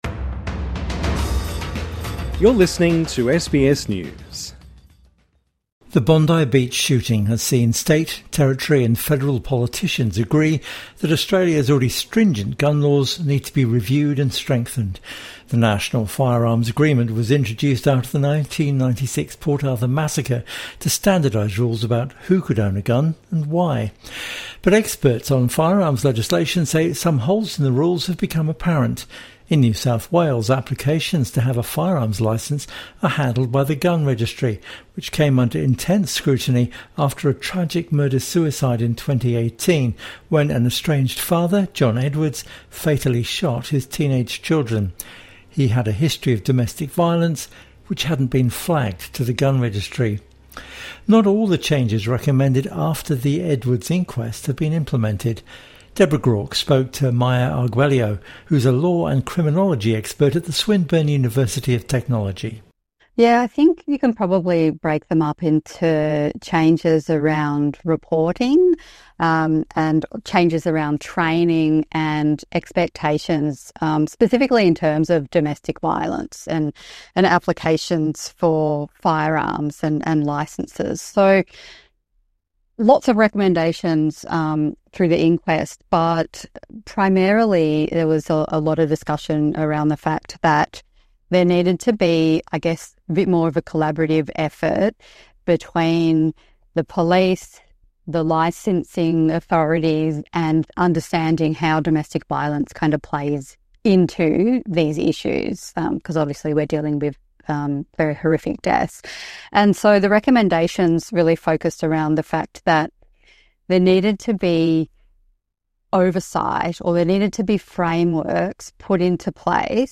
INTERVIEW: What needs to be done about our gun laws?